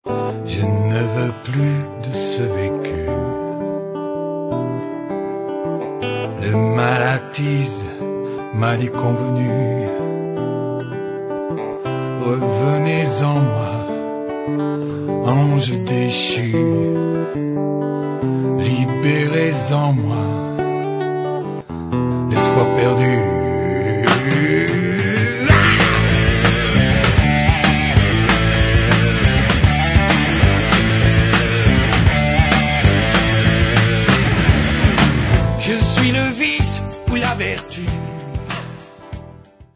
Groupe Rock PAU